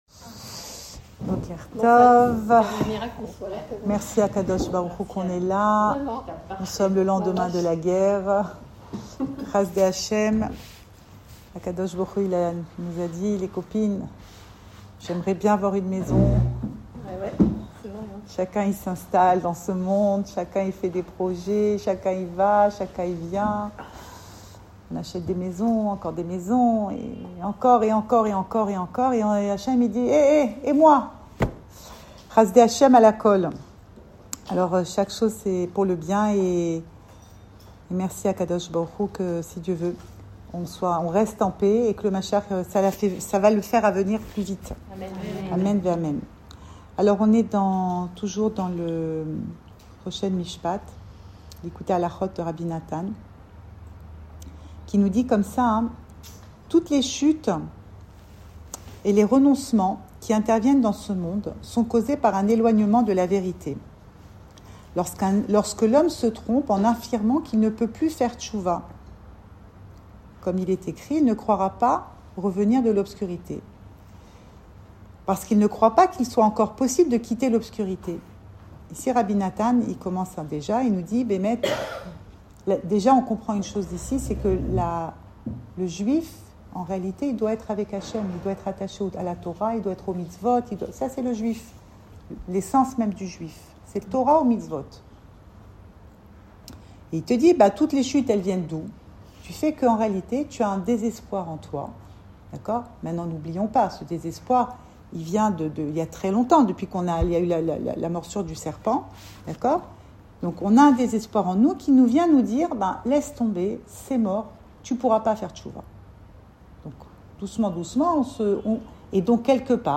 L’alibi Cours audio Emouna Le coin des femmes Le fil de l'info Pensée Breslev
Enregistré à Tel Aviv